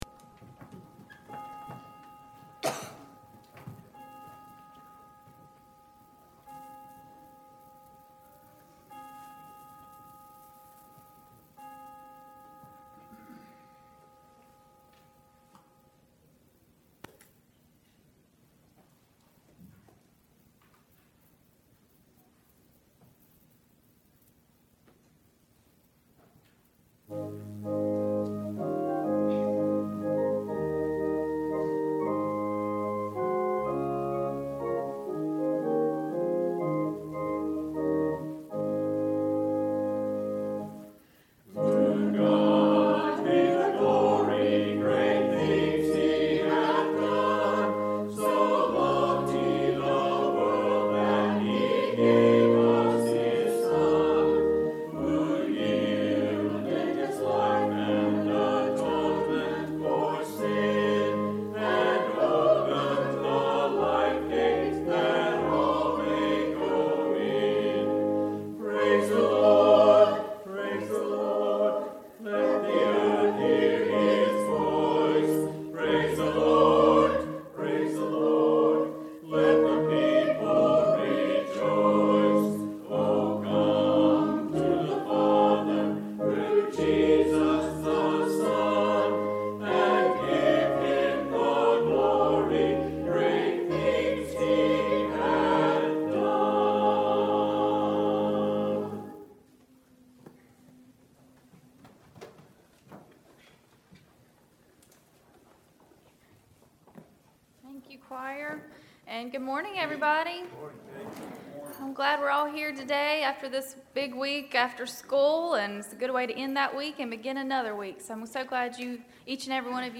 2 Service Type: Sunday Worship Topics